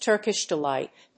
アクセントTúrkish delíght
音節Tùrkish delíght